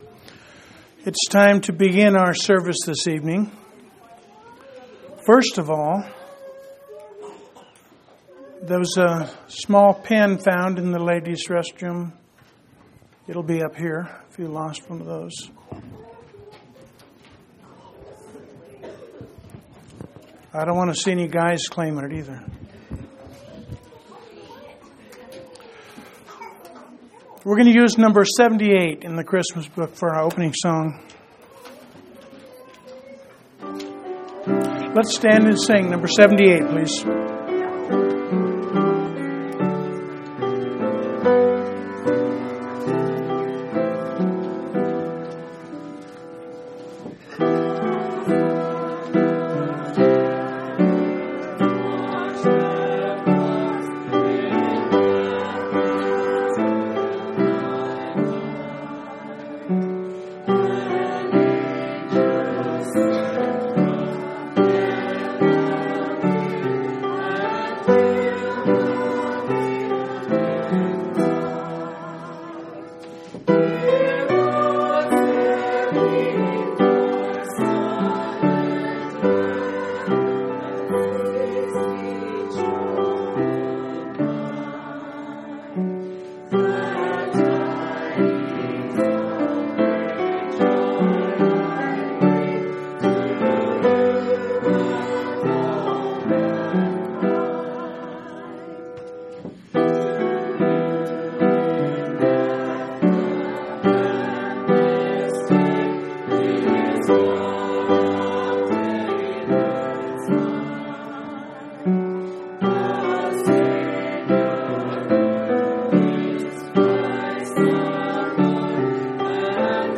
12/14/2003 Location: Phoenix Local Event